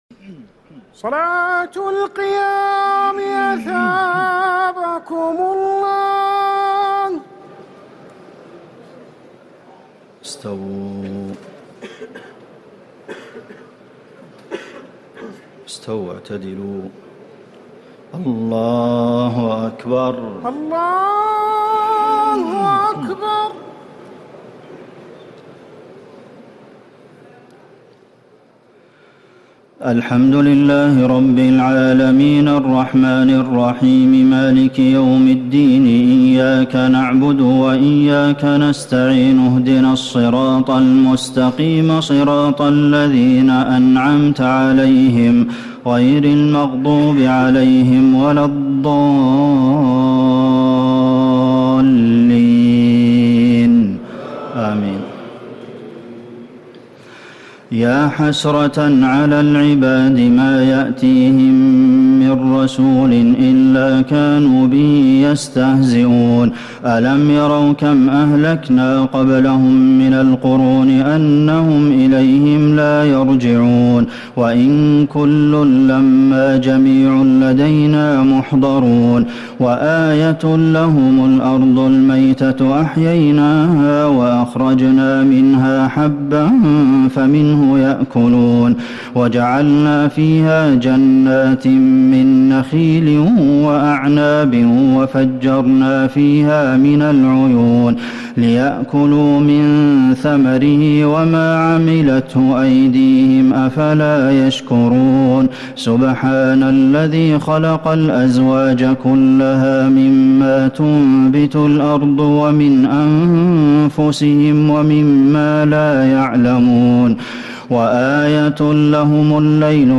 تراويح ليلة 22 رمضان 1439هـ من سور يس (30-83) والصافات(1-138) Taraweeh 22 st night Ramadan 1439H from Surah Yaseen and As-Saaffaat > تراويح الحرم النبوي عام 1439 🕌 > التراويح - تلاوات الحرمين